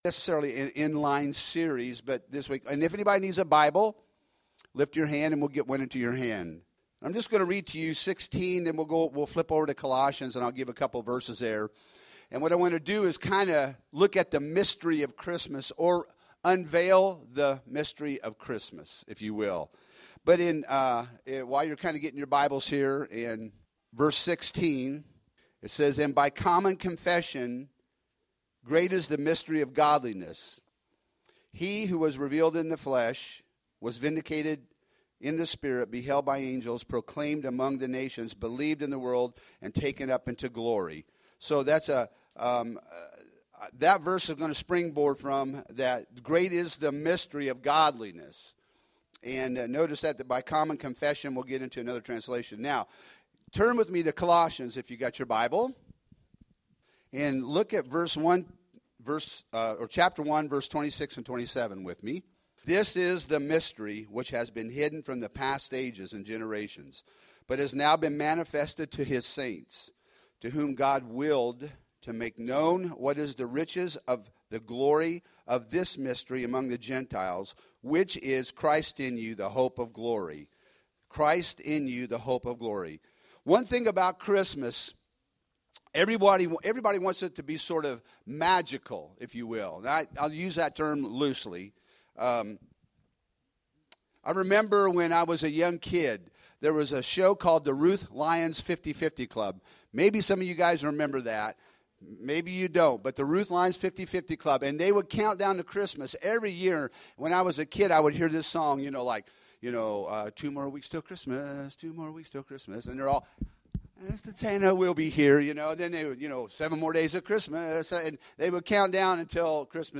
Home › Sermons › Light Came Down